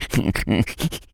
rabbit_squeak_01.wav